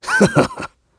Clause_ice-Vox_Happy5.wav